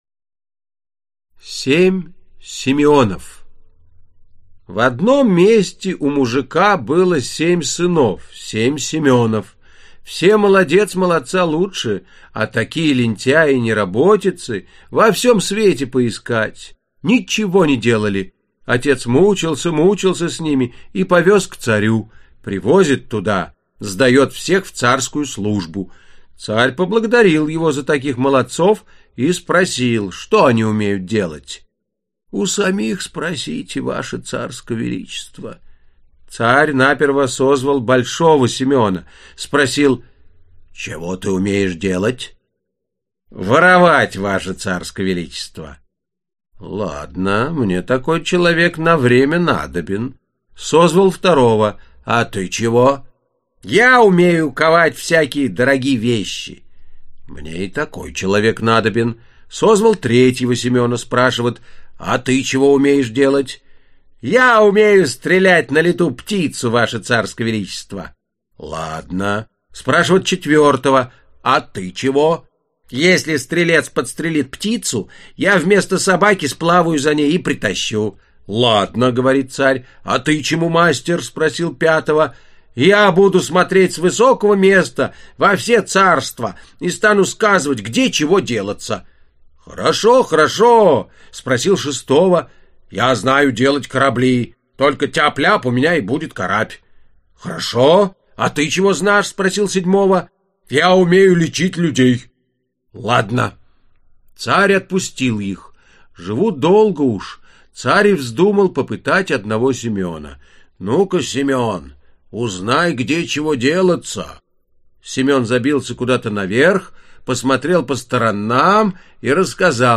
Аудиокнига Царевна-лягушка (сборник) | Библиотека аудиокниг
Aудиокнига Царевна-лягушка (сборник) Автор Сборник Читает аудиокнигу Вениамин Смехов.